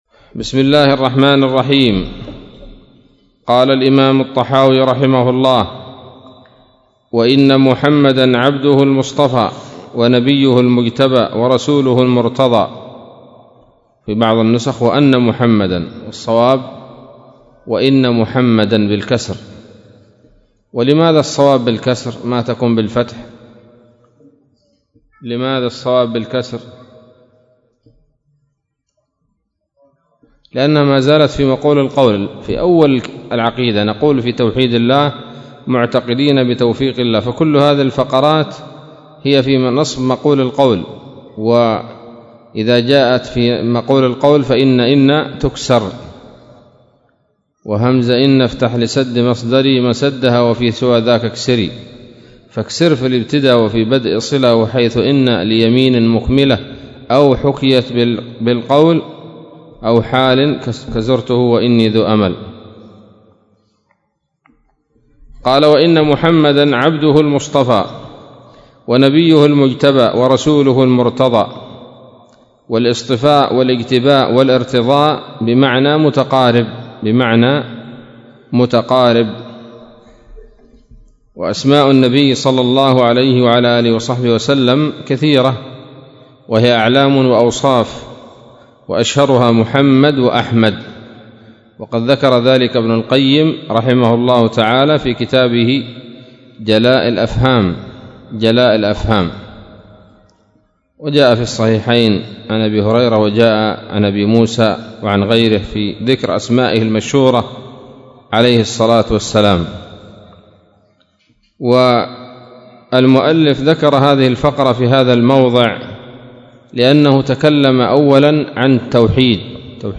الدرس التاسع من شرح العقيدة الطحاوية